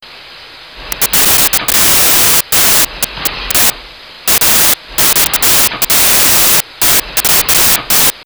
Westinghouse Pump Ref
pump.wav